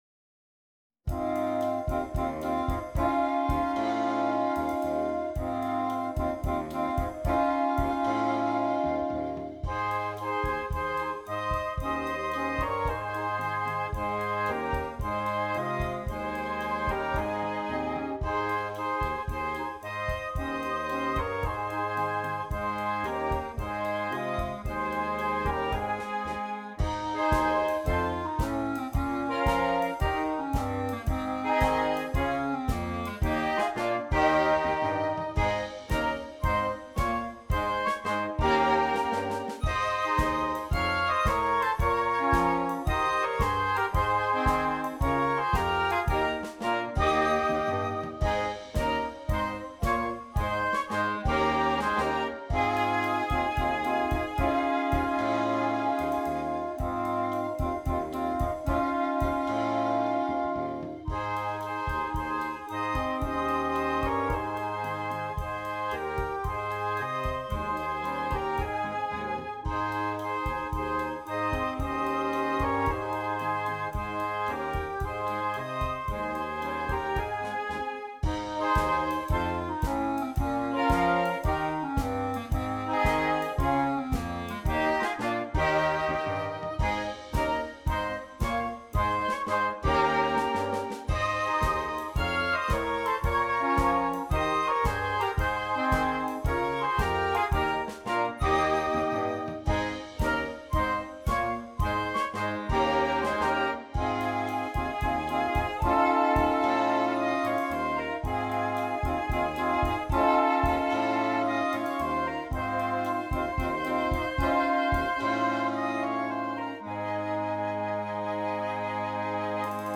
Interchangeable Woodwind Ensemble
Traditional Carol